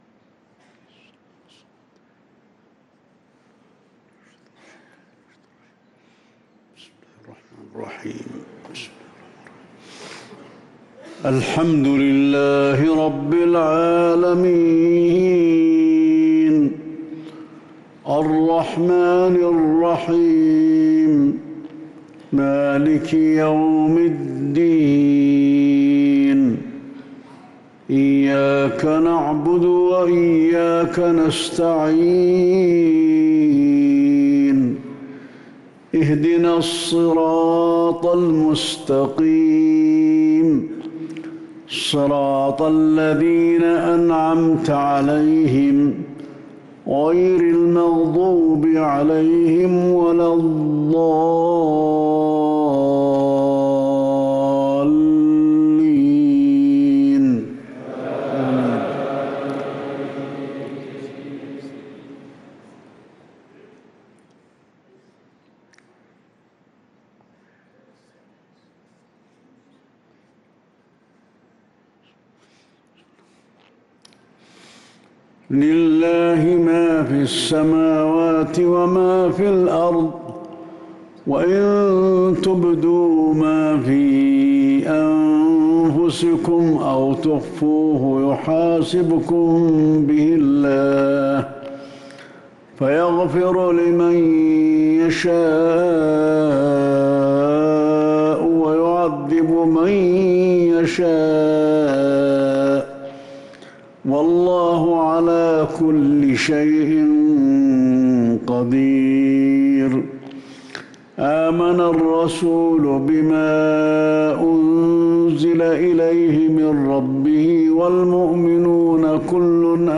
صلاة المغرب للقارئ علي الحذيفي 10 ربيع الآخر 1445 هـ
تِلَاوَات الْحَرَمَيْن .